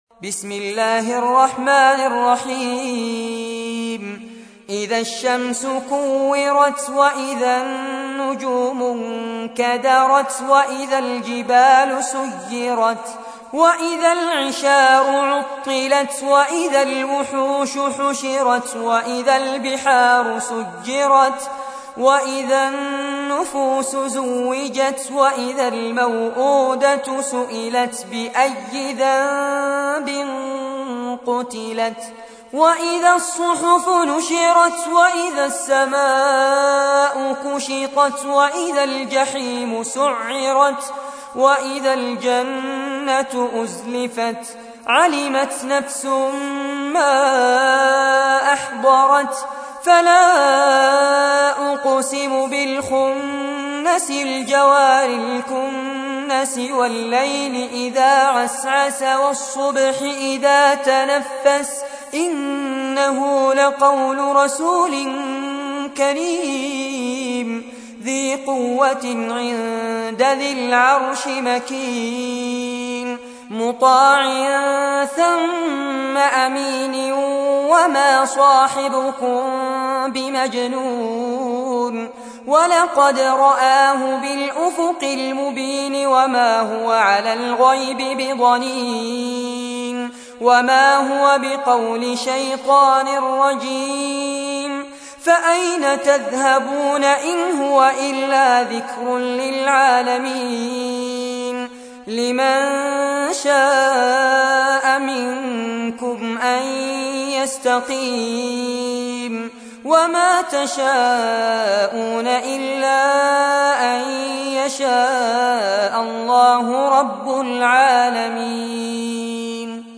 تحميل : 81. سورة التكوير / القارئ فارس عباد / القرآن الكريم / موقع يا حسين